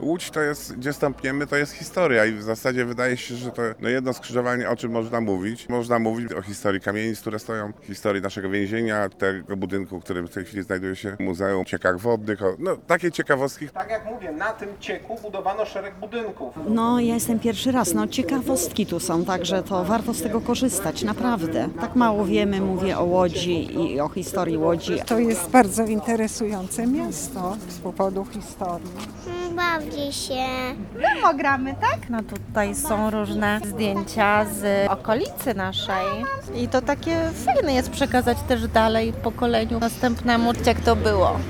Na dziedzińcu Muzeum Tradycji Niepodległościowych w Łodzi zorganizowano piknik połączony z historycznym spacerem z przewodnikiem po okolicy.
obrazek-piknik.mp3